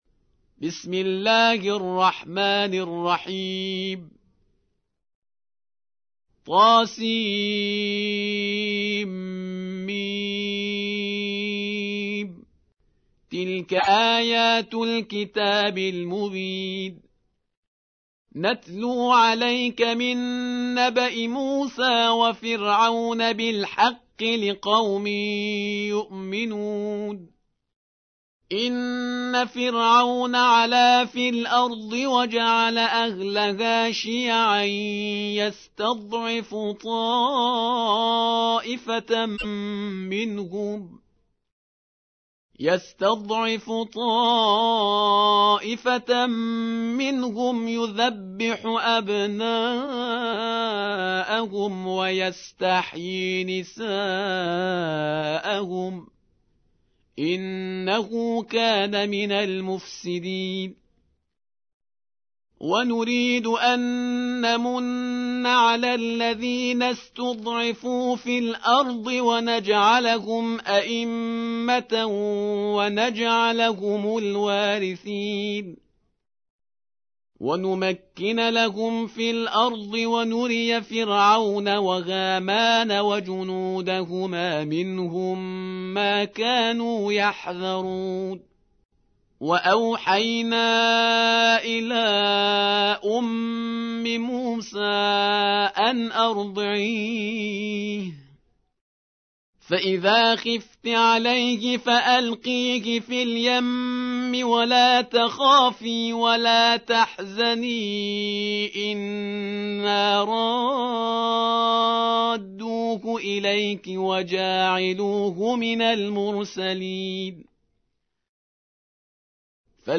28. سورة القصص / القارئ